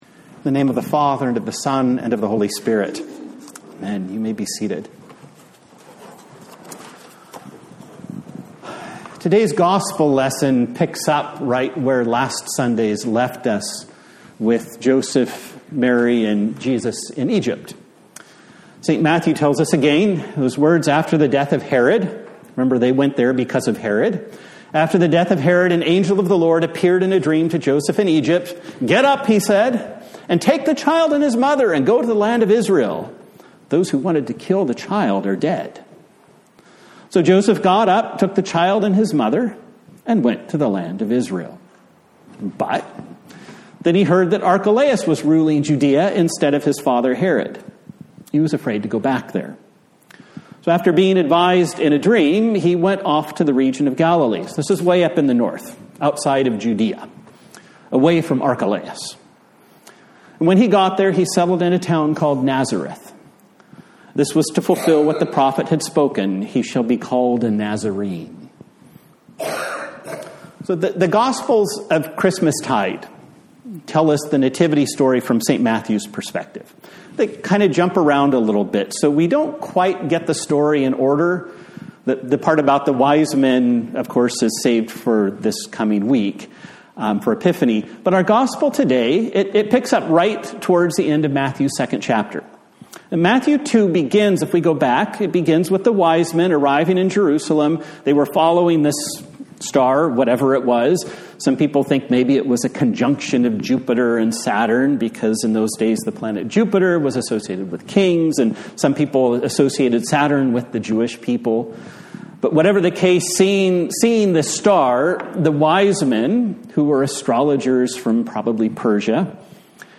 A Sermon for the Second Sunday after Christmas